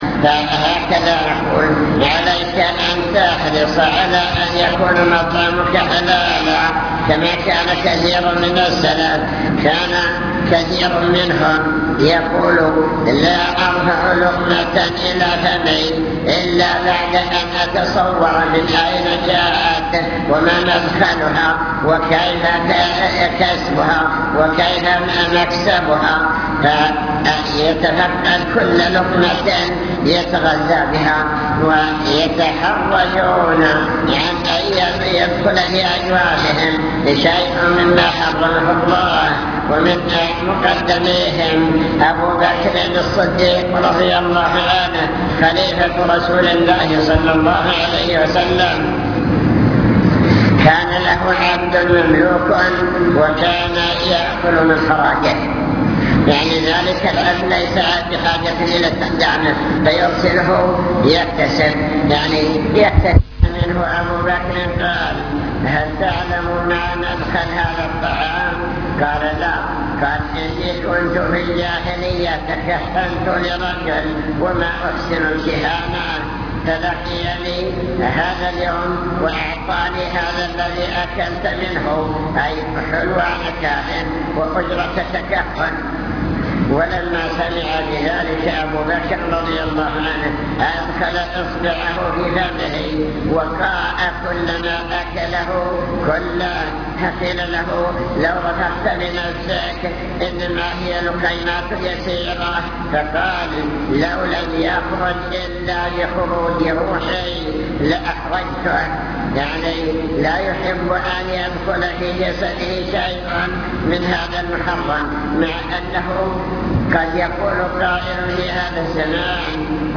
المكتبة الصوتية  تسجيلات - محاضرات ودروس  أطب مطعمك